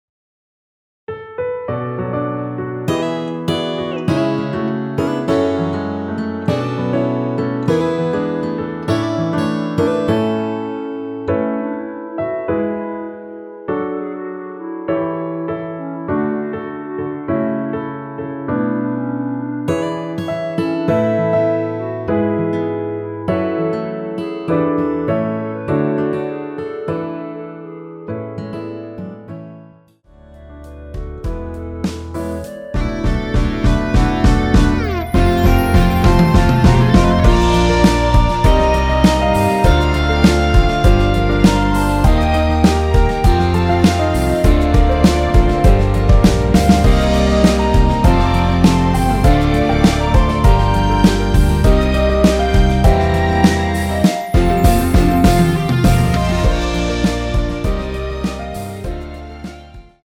원키 멜로디 포함된 MR입니다.(미리듣기 확인)
멜로디 MR이라고 합니다.
앞부분30초, 뒷부분30초씩 편집해서 올려 드리고 있습니다.
중간에 음이 끈어지고 다시 나오는 이유는